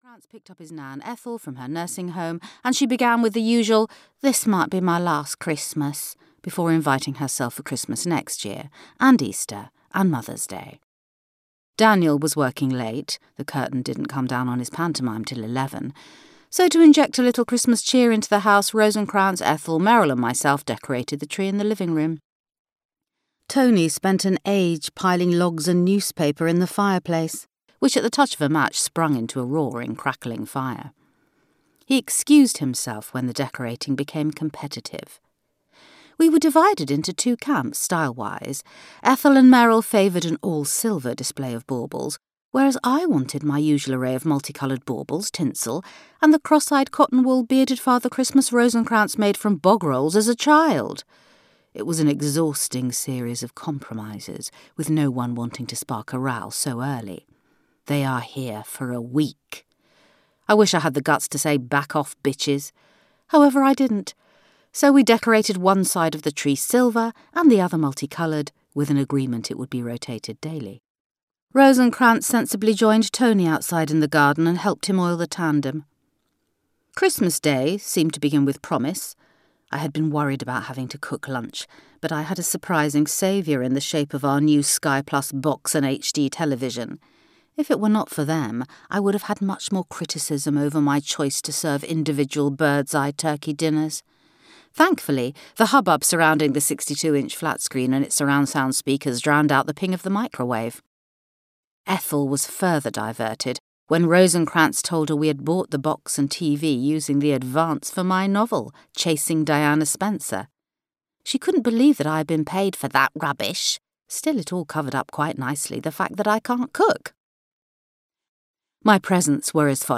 Audio knihaThe Not So Secret Emails of Coco Pinchard
Ukázka z knihy